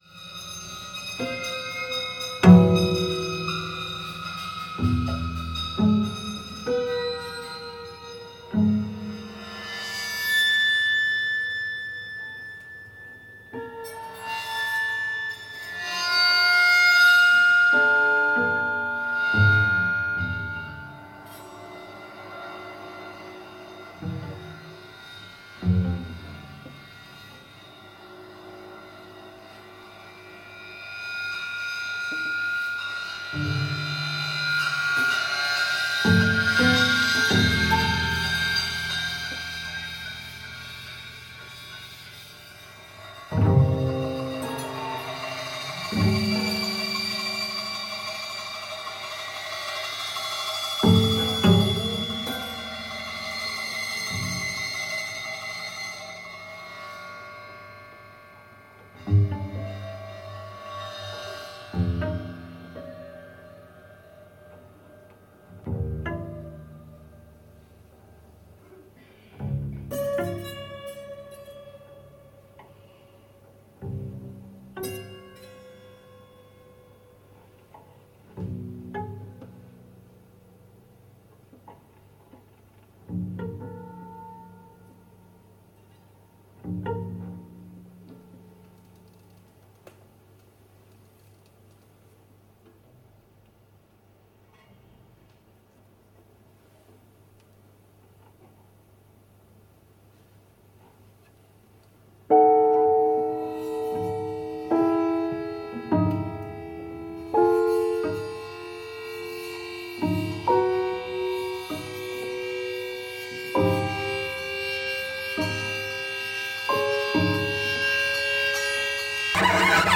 piano
contrabass